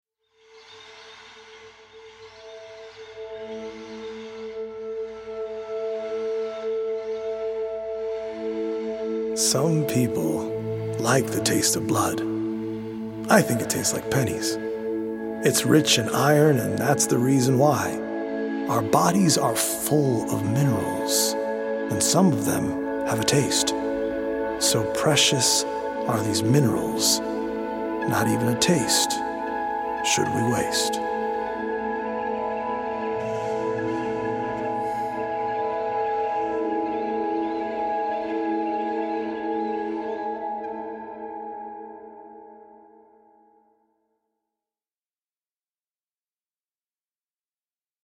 poetic recordings
healing Solfeggio frequency music
EDM producer